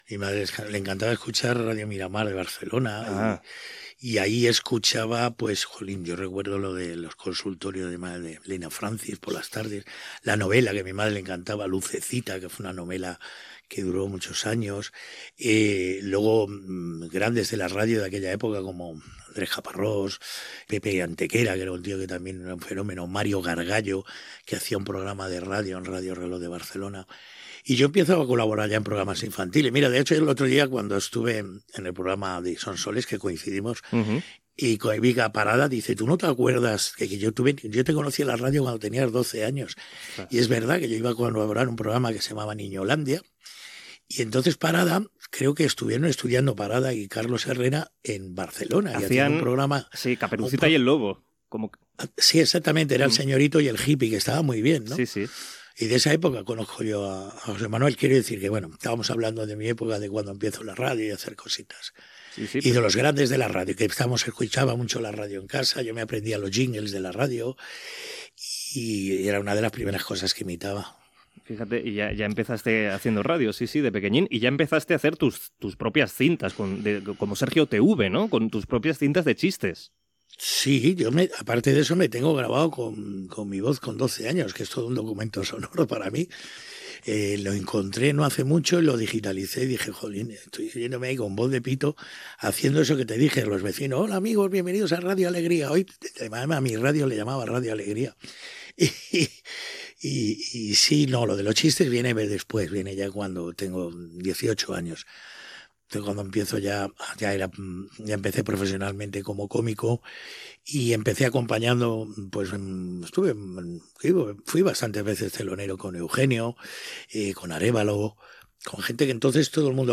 Fragments d'una entrevista a l'humorista Juan Muñoz (Cruz y Raya), amb els seus records de la ràdio barcelonina.
Entreteniment